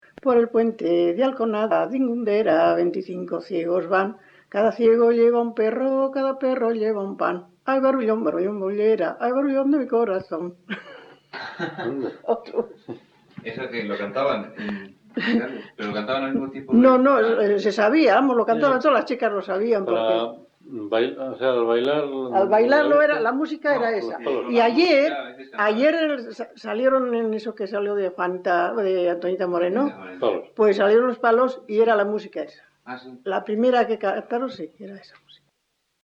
Sección: Registros sonoros
Grabación realizada en La Overuela (Valladolid), en 1977.
Canciones populares Icono con lupa